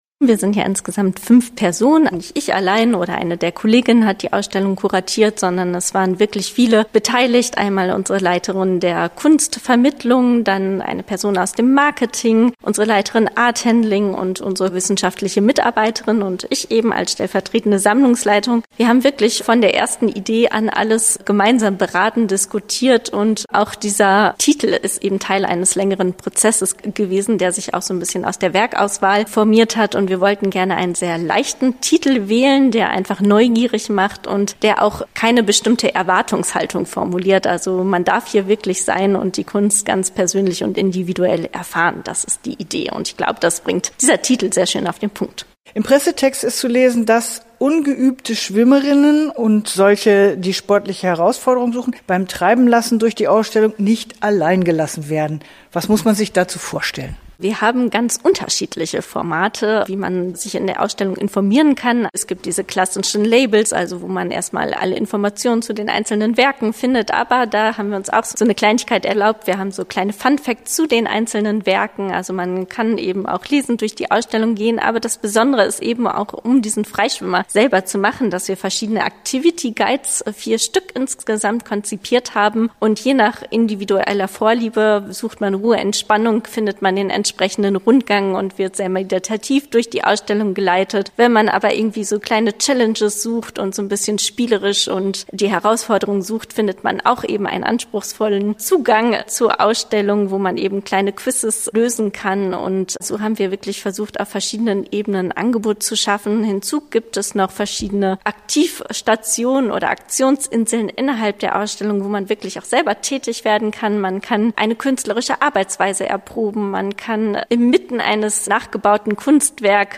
BIS-ENDE-SEP-25-Interview-Freischwimmen-WOB_ewb.mp3